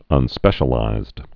(ŭn-spĕshə-līzd)